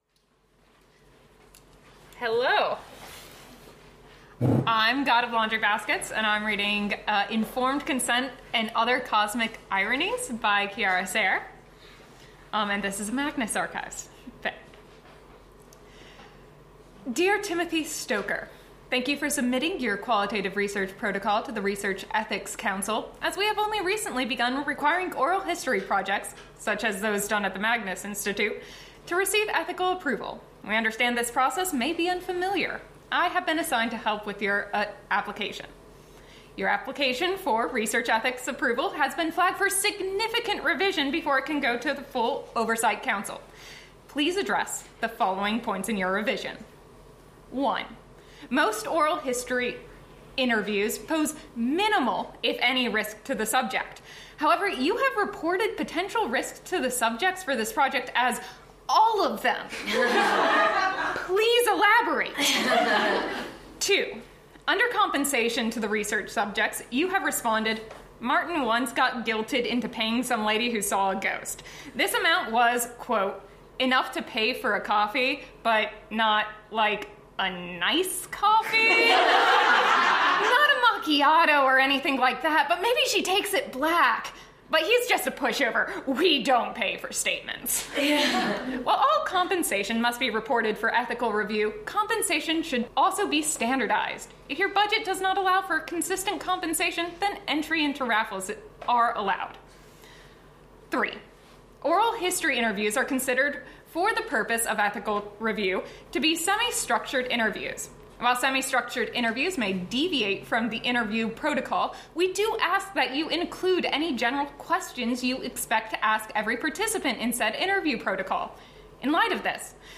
M4A - Podfication 2020 Live preformance